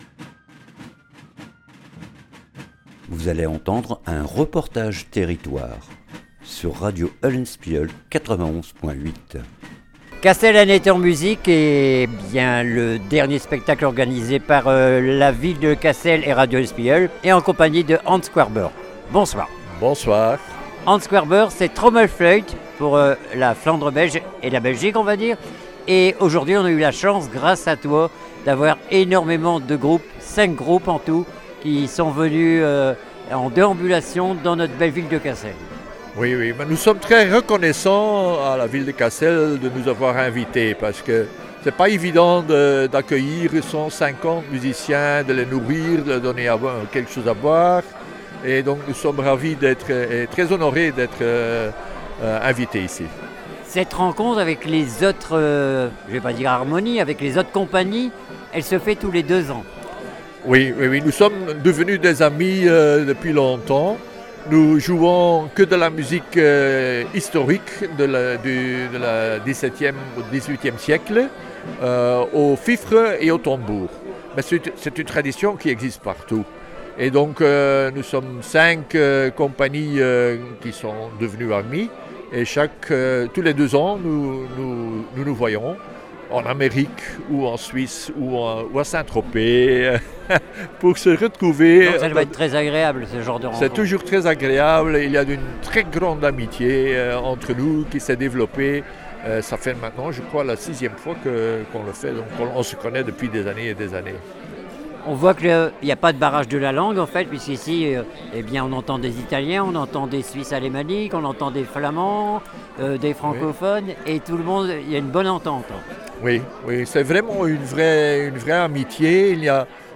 REPORTAGE TERRITOIRE TROMMEL FLUIT